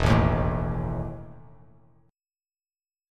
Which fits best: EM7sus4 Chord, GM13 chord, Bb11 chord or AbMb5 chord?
EM7sus4 Chord